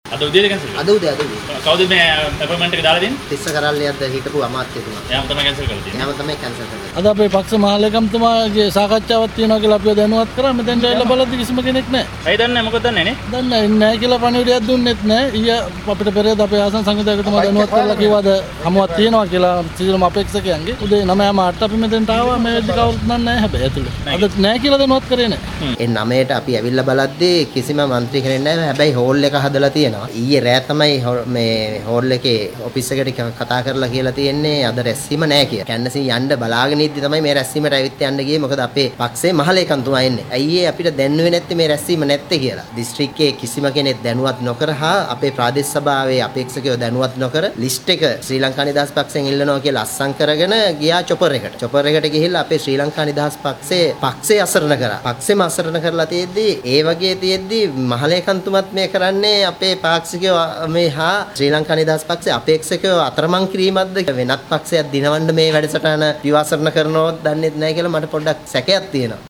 මේ පිළිබඳව පක්ෂ සාමාජිකයින් දැක්වූ අදහස්.